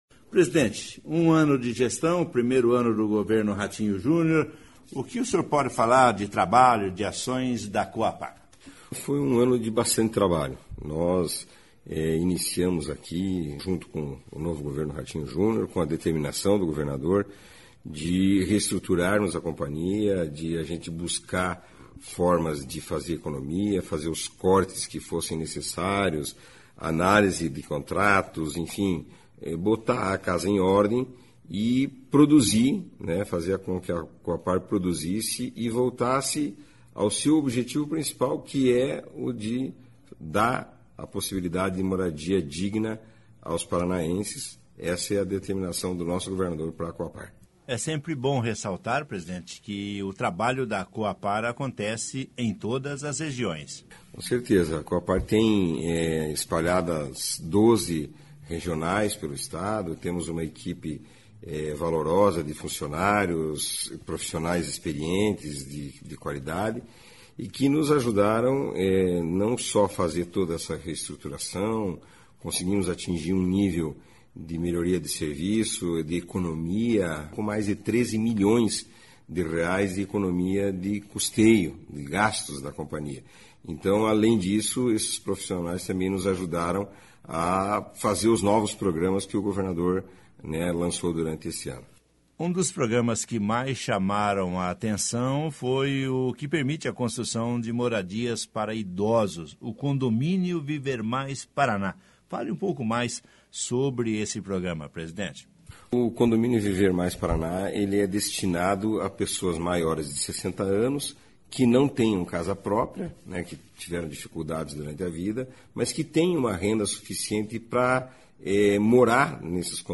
ENTREVISTA COM O PRESIDENTE DA COHAPAR, JORGE LANGE